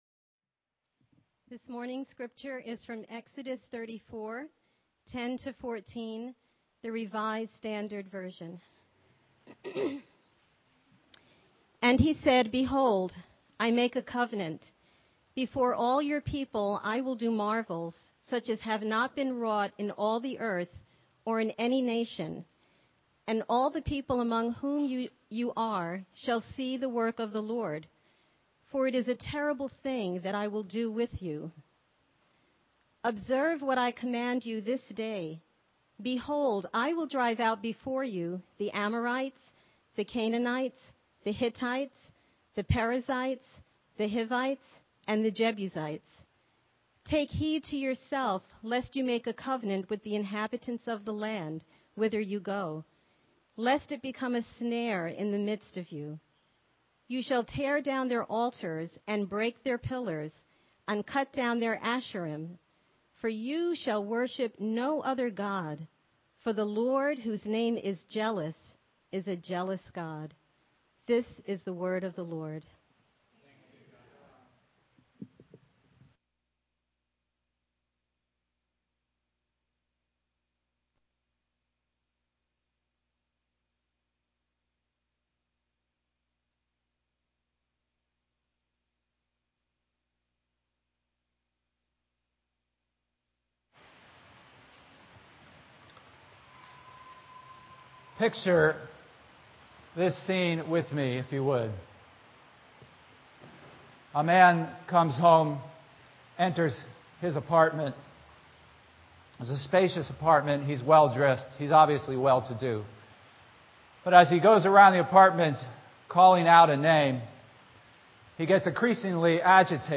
The sermon also includes a brief look at the solar system and some bad acting….